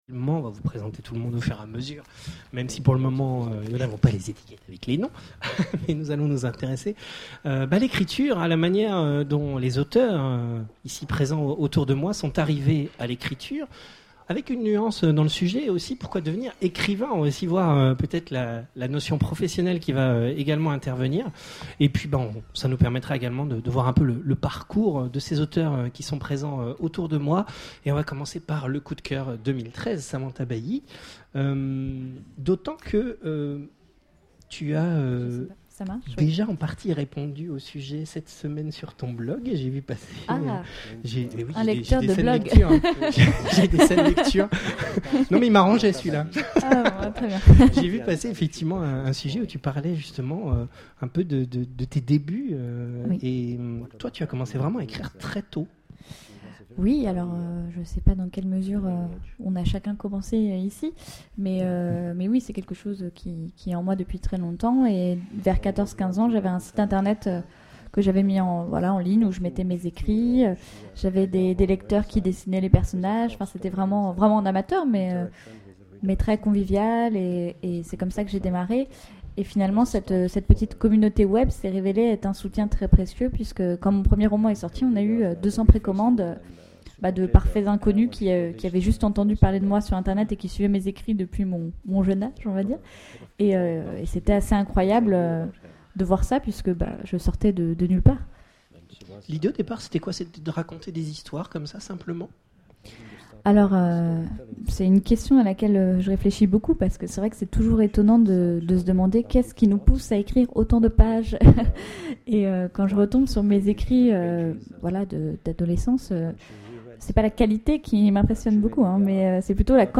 Imaginales 2013 : Conférence Pourquoi écrire ?